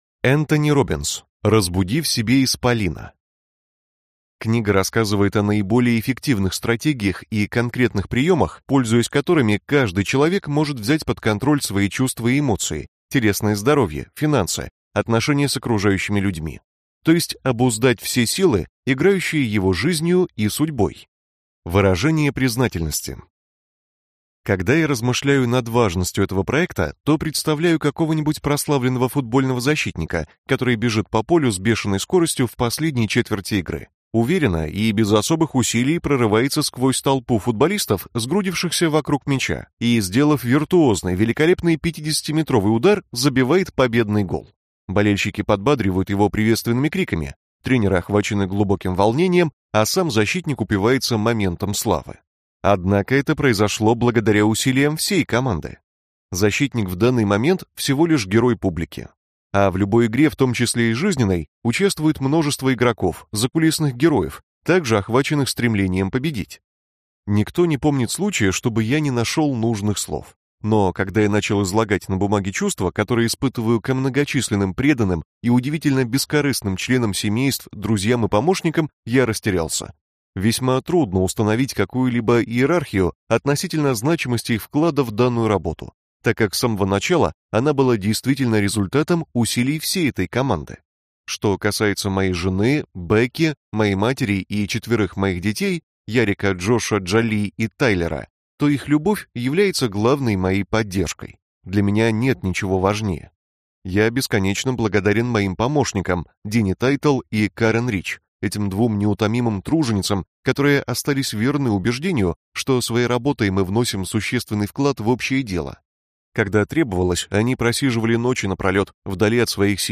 Аудиокнига Разбуди в себе исполина - купить, скачать и слушать онлайн | КнигоПоиск